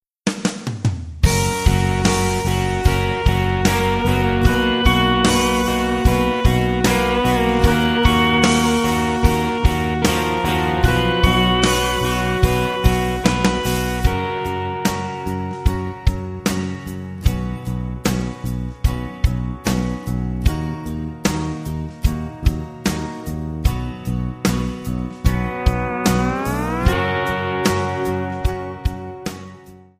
F#
Backing track Karaoke
Country, 2000s